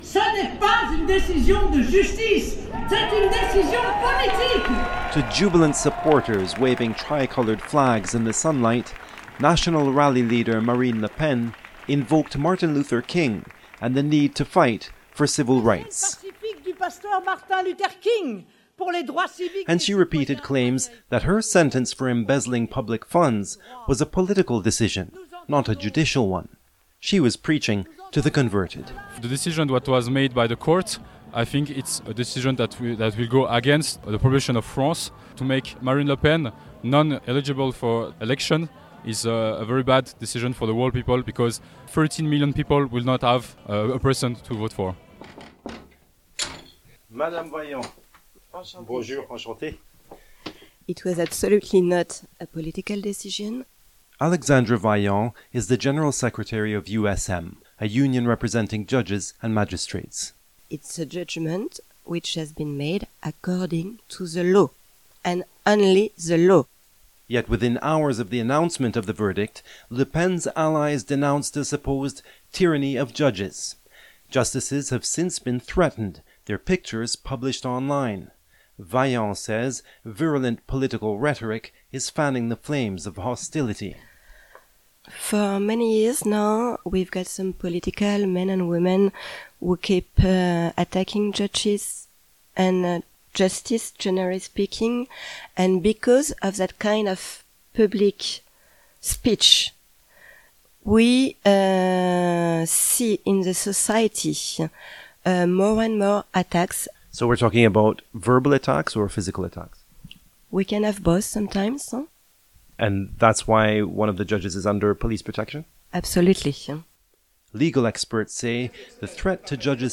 I filed the report below for CBC Radio on April 6, after attending all three rallies, where each camp claimed to be defending democracy…some more convincingly than others.